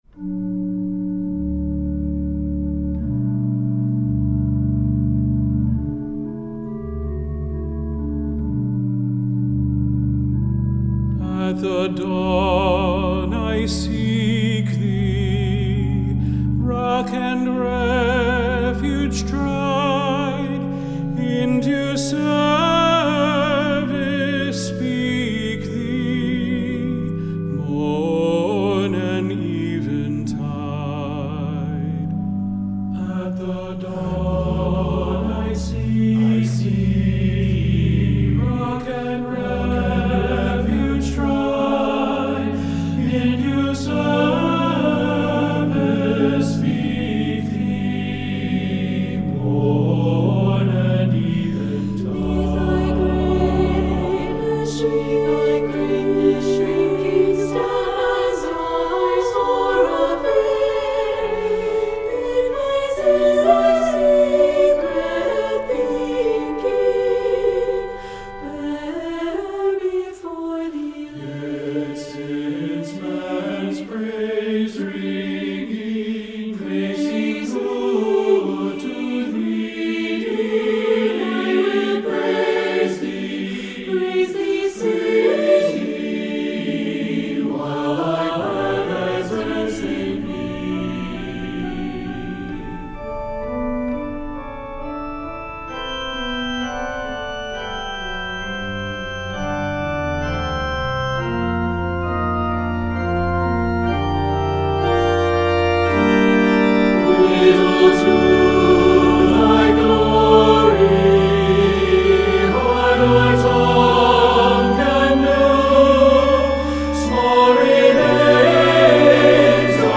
SATB with organ